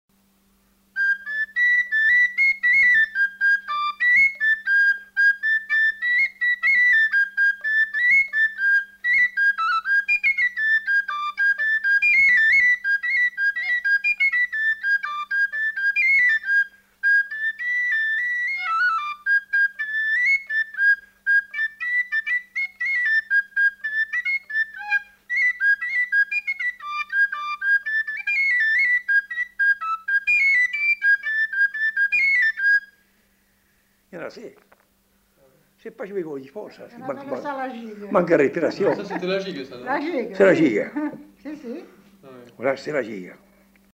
Aire culturelle : Bazadais
Genre : morceau instrumental
Instrument de musique : flûte à trois trous
Danse : gigue